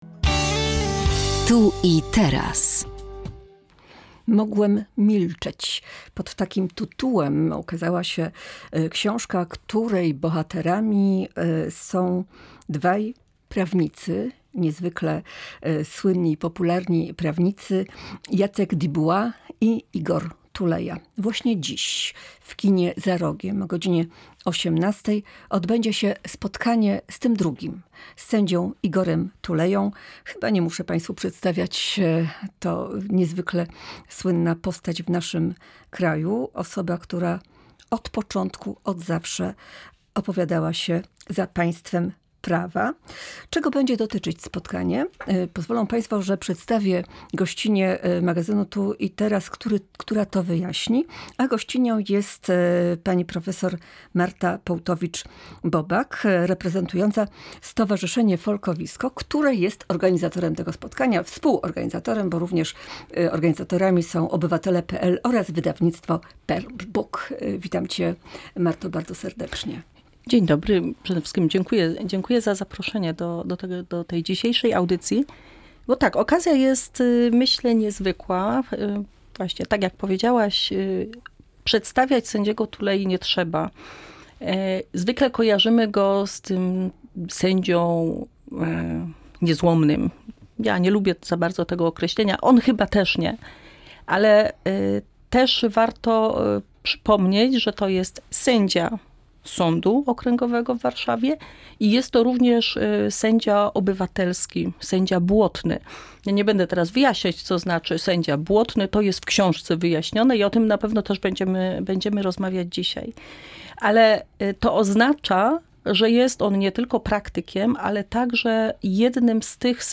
rozmowa-1.mp3